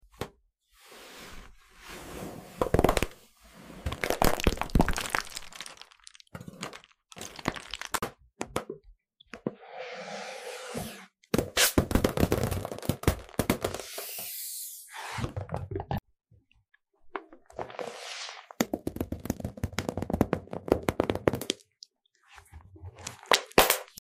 Which Ballon Pop Sound More Sound Effects Free Download